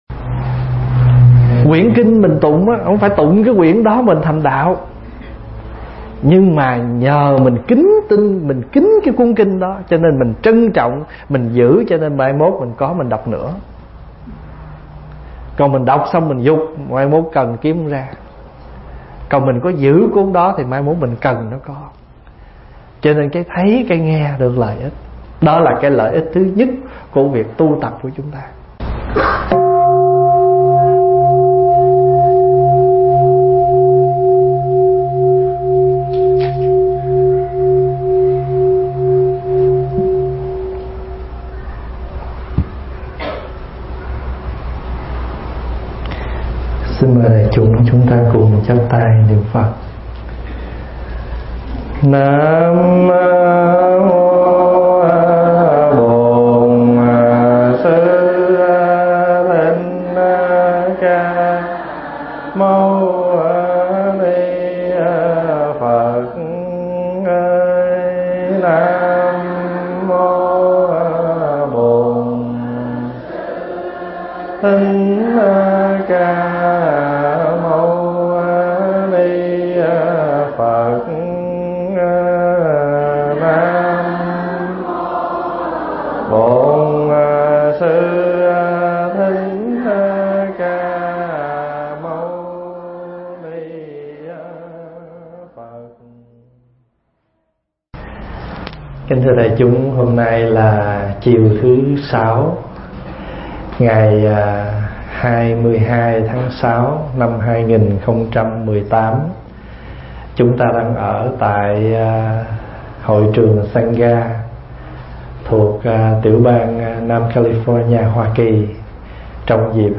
Nghe Mp3 thuyết pháp Lợi Ích Tu Học
thuyết giảng tại Thiền Đường Mây Từ (Santa Ana)